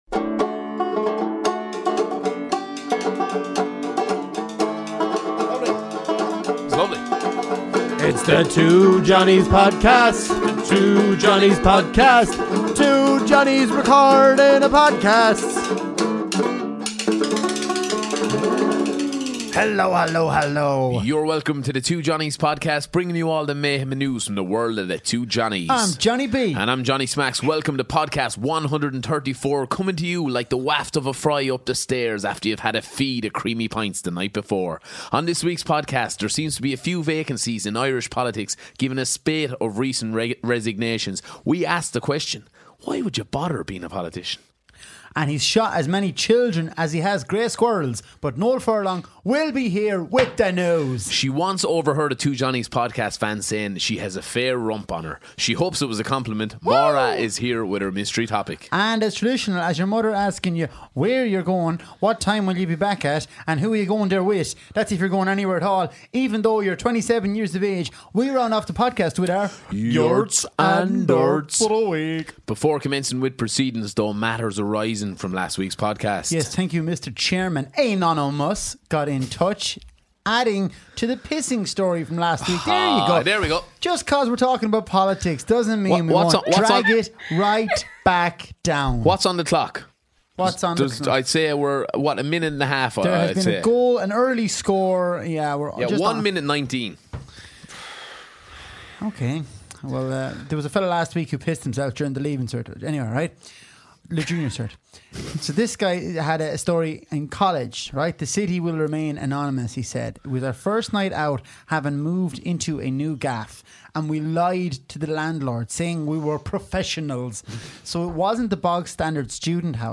Ireland's favourite comedy duo tackle the Big issues, this week: